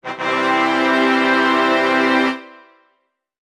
epic_win.mp3